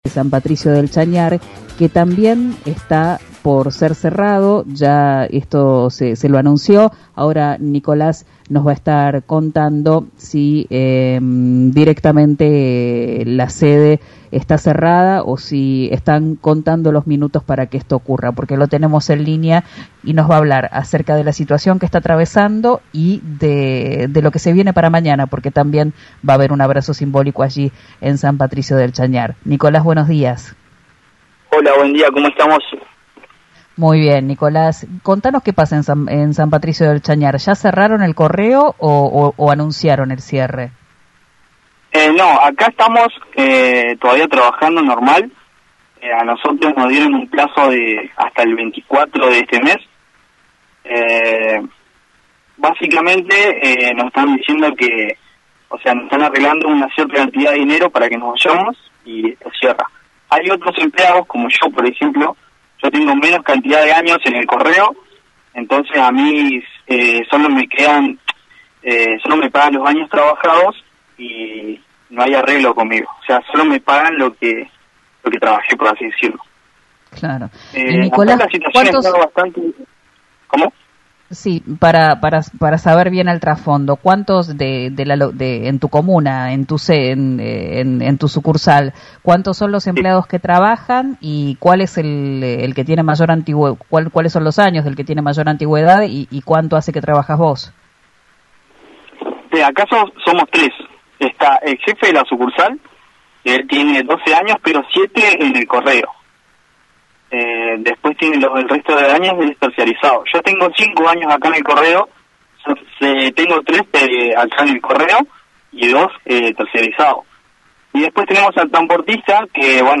Manifestó en diálogo con RN Radio que ahora la atención es normal pero que se siente la preocupación entre los vecinos por los avisos del cierre.